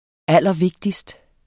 Udtale [ ˈalˀʌˈvegdisd ]